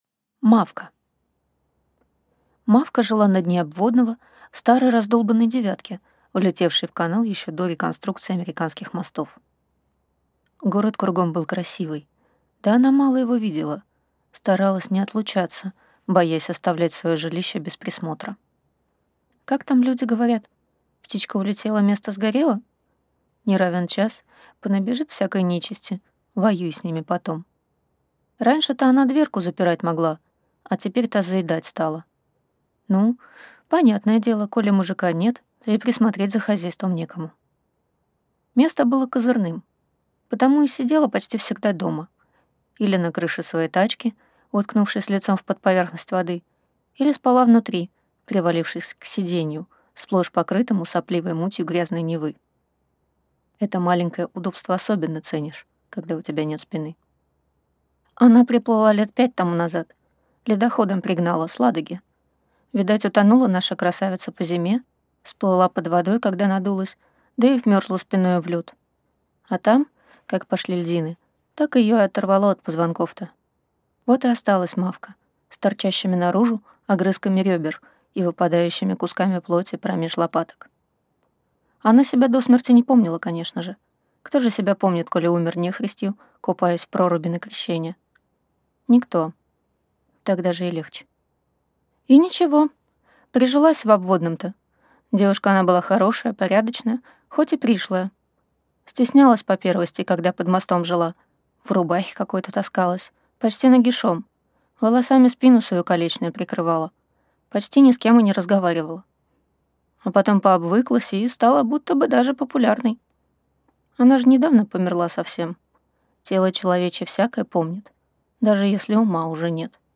Аудиокнига Мавка | Библиотека аудиокниг